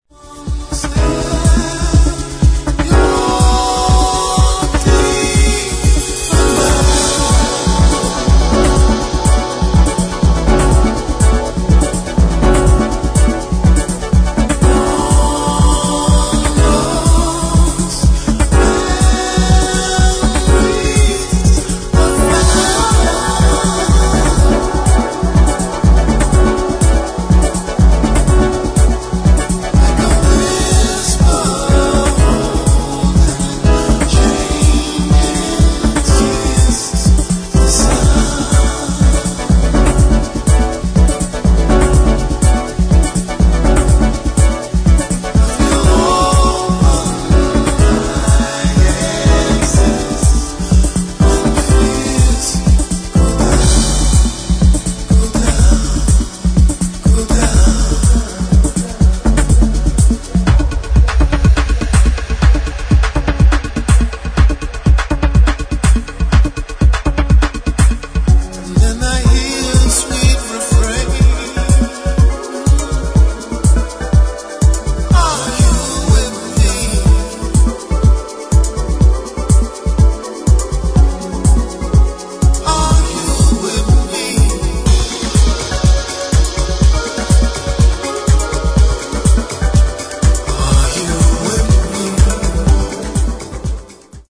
[ HOUSE / TECHNO ]